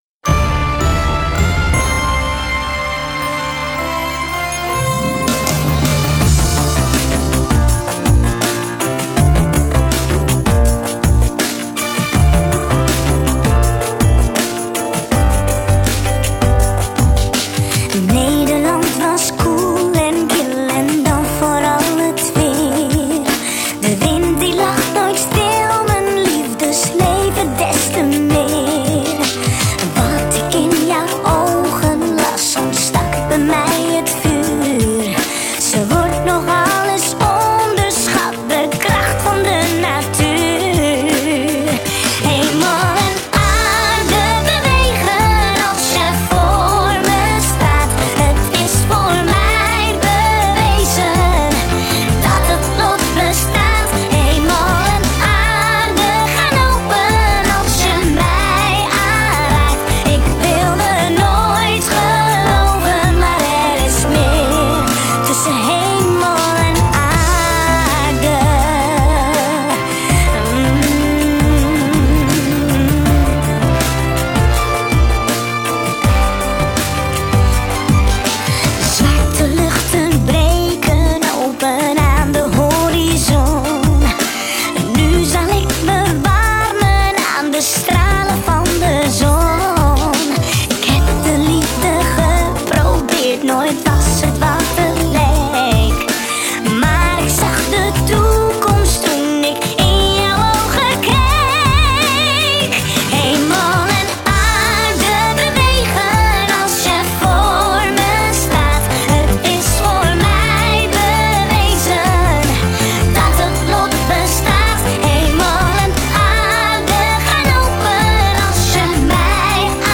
het hele nummer